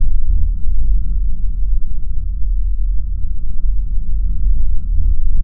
sci-fi_sub_bass_rumble_loop.wav